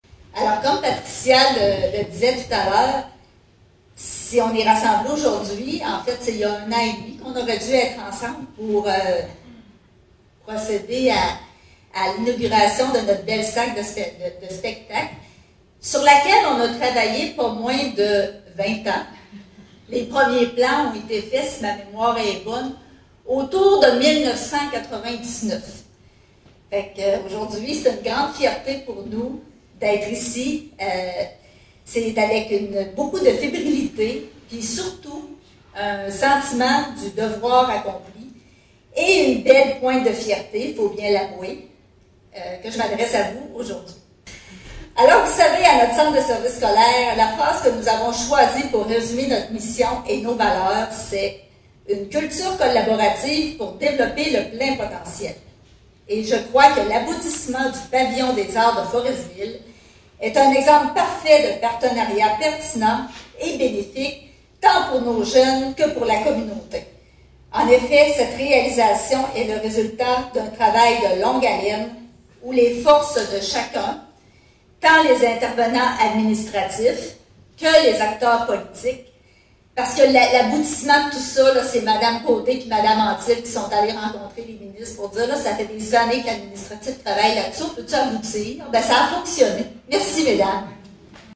Lundi 27 septembre dernier avait lieu l’inauguration du Pavillon des Arts de Forestville.